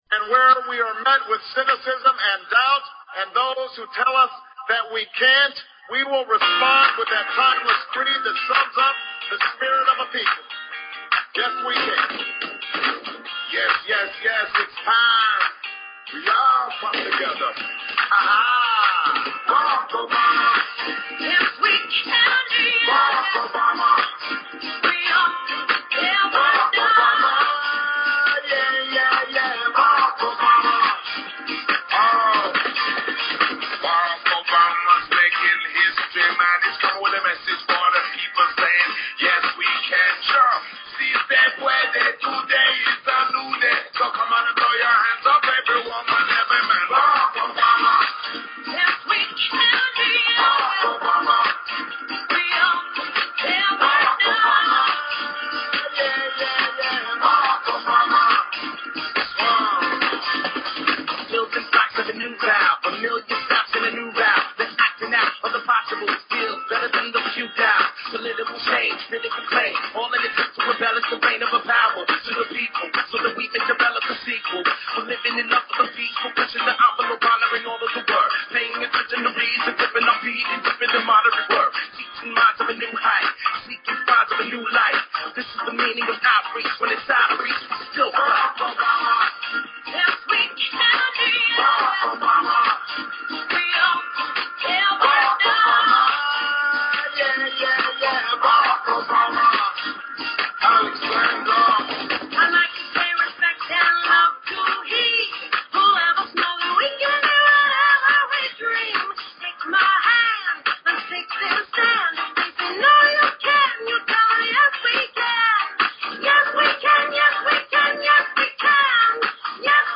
Talk Show Episode, Audio Podcast, The_Galactic_Round_Table and Courtesy of BBS Radio on , show guests , about , categorized as